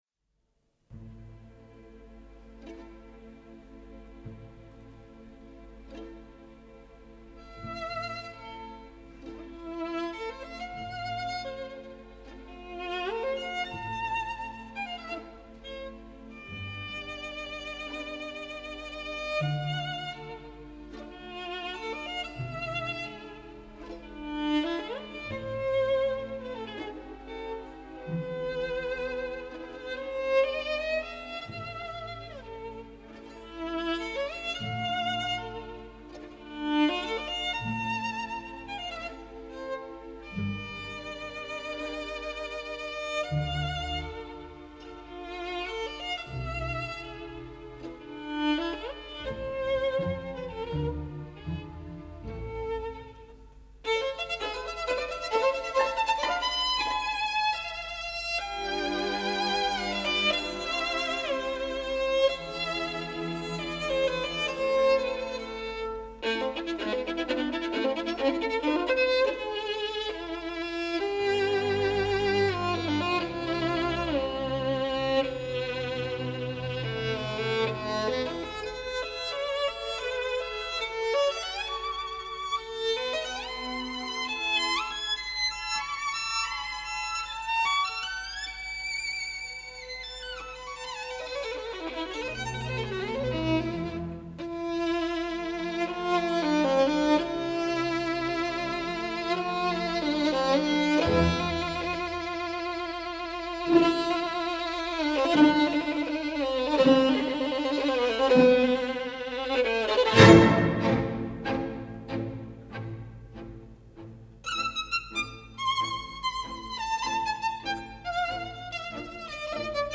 скрипка